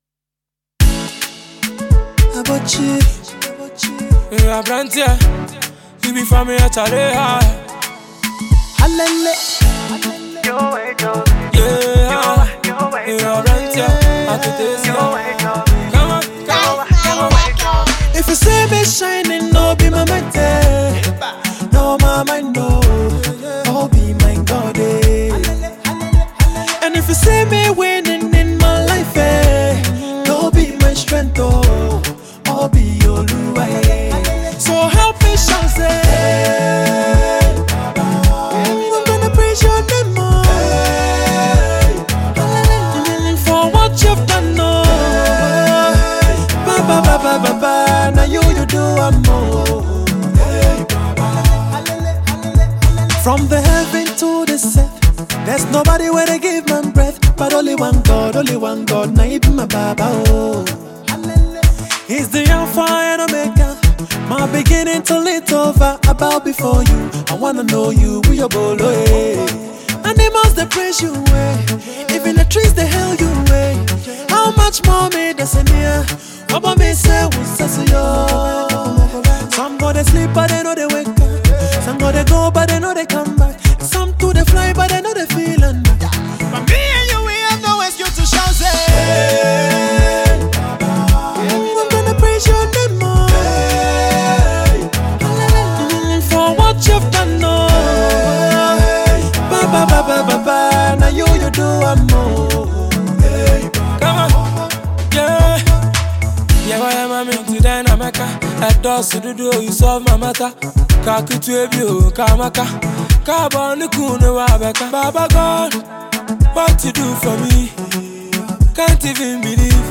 Ghana Music
highlife singer